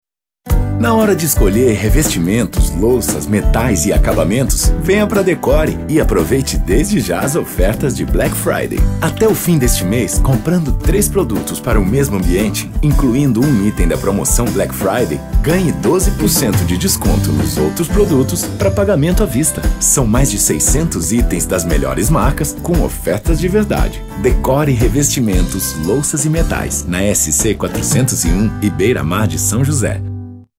Masculino
Voz grave coloquial.